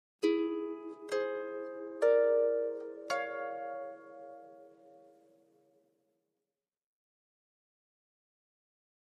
Harp, Slow Arpeggio, Type 3 - In Two Voices, 7th Chord